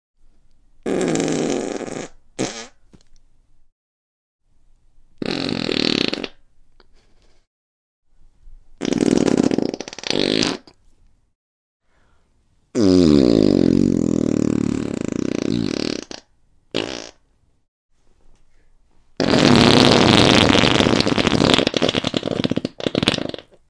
Farts, loud and obnoxious x5 sound effects free download